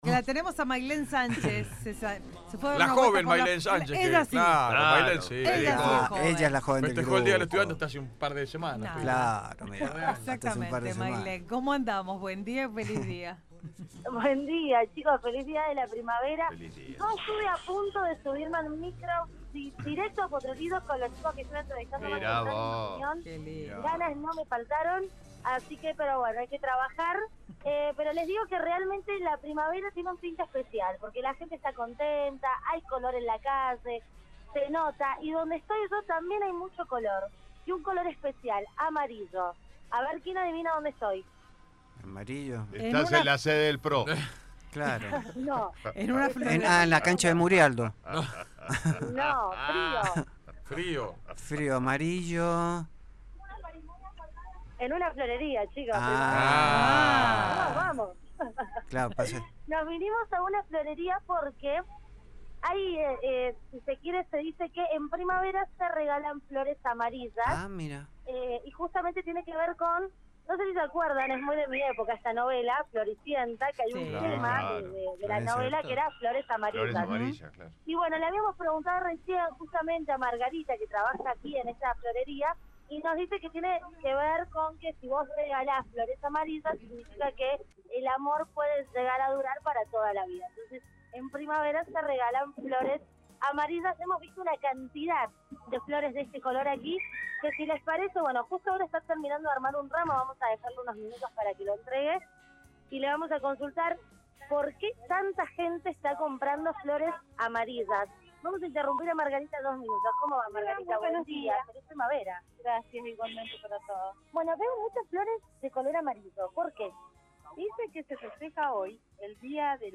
LVDiez - Radio de Cuyo - Móvil de LVDiez